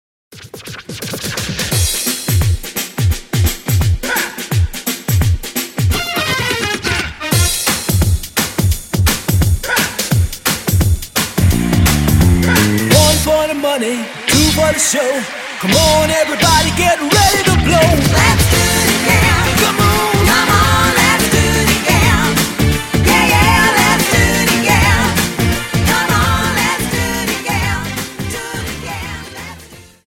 Dance: Jive Song